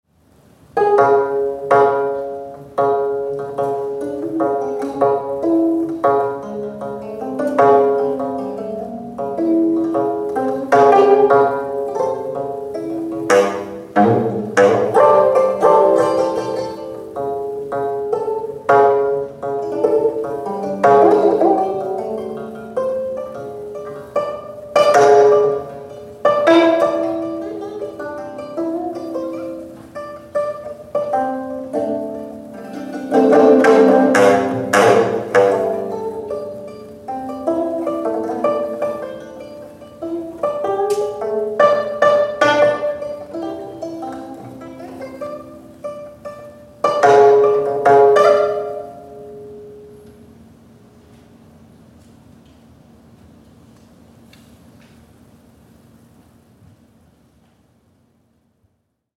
Unaccompanied Sanjo Gayageum (Korean plucked string), 7min
12-String Sanjo Gayageum